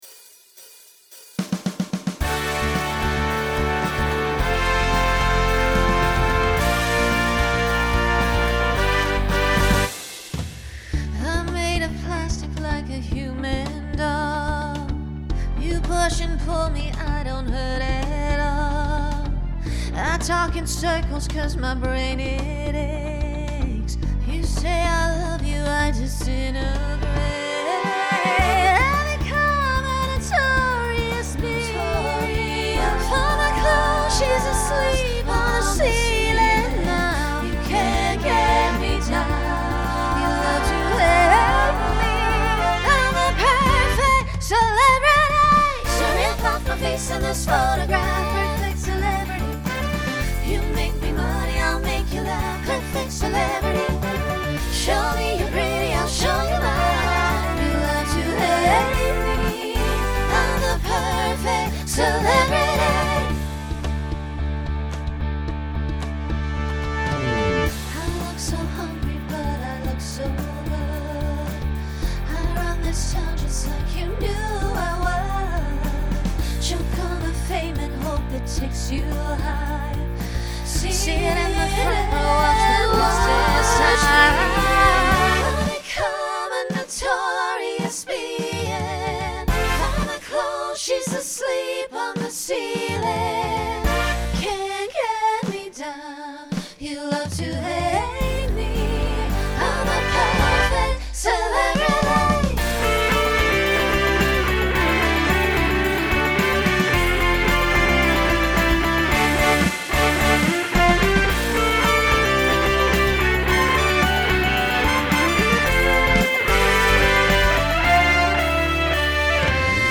Genre Pop/Dance Instrumental combo
Mid-tempo , Solo Feature Voicing SSA